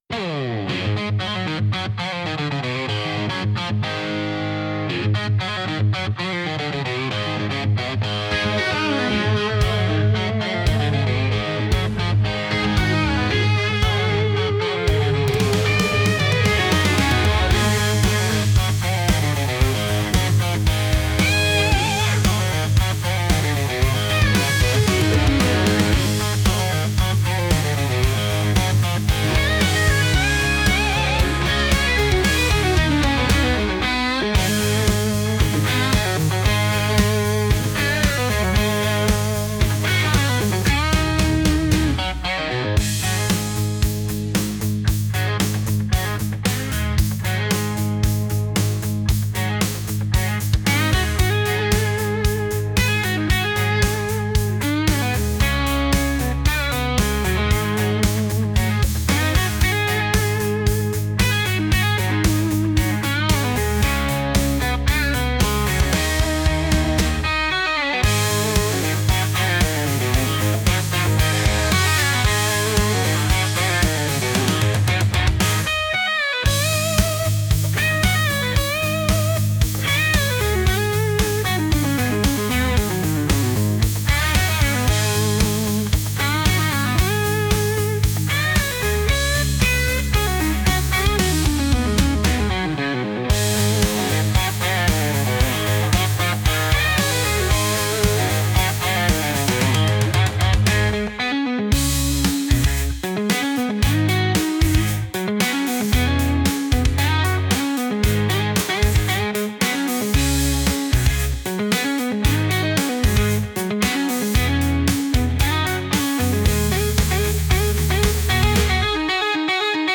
Instrumental - Real Liberty Media Station Music 8_01.mp3